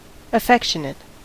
Ääntäminen
UK : IPA : [ə.ˈfɛk.ʃə.nət]